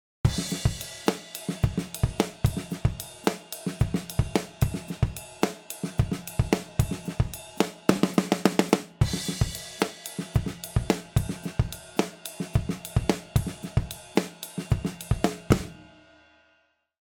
The second half of the pattern goes linear, and ends up somewhere altogether different.
The quarter-note bell ride is still here. The offbeat high-hat is still here. The ghost notes are still here.
1 + 2 + 3 + 4 +     (1/4 = 110 bpm)
notable!the "illegitimate cousin" groove